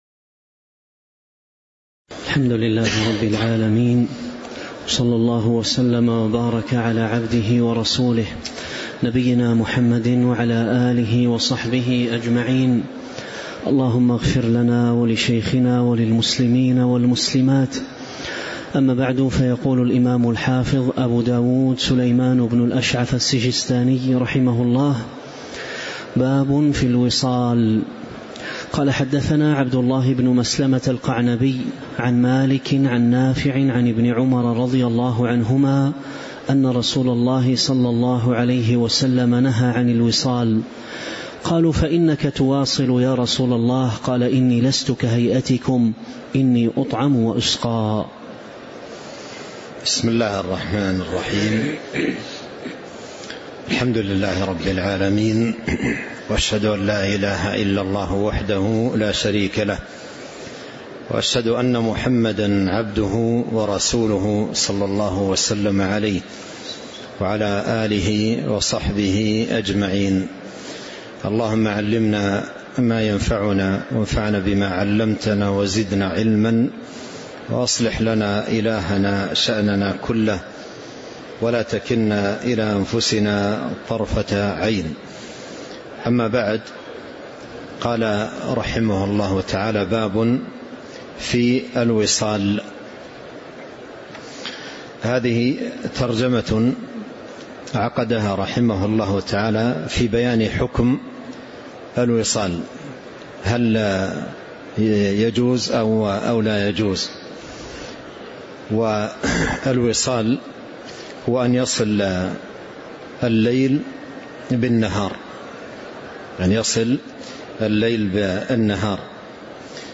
تاريخ النشر ٨ رمضان ١٤٤٦ هـ المكان: المسجد النبوي الشيخ: فضيلة الشيخ عبد الرزاق بن عبد المحسن البدر فضيلة الشيخ عبد الرزاق بن عبد المحسن البدر قوله: باب في الوصال (08)